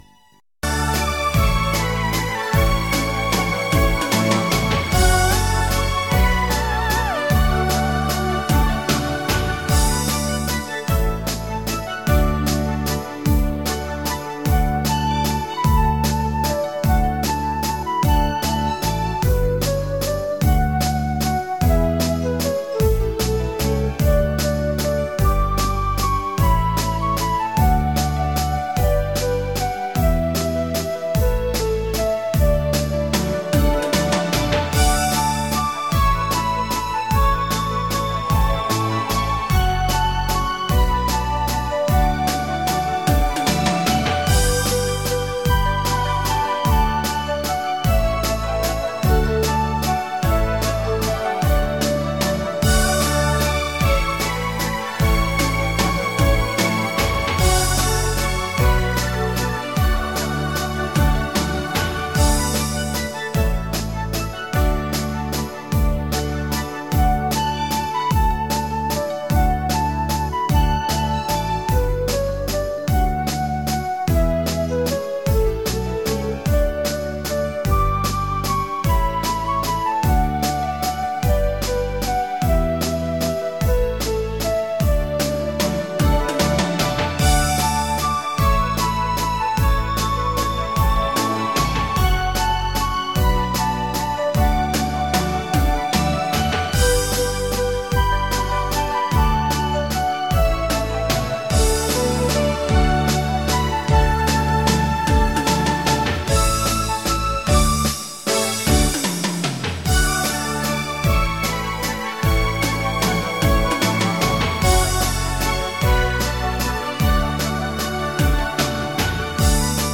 快三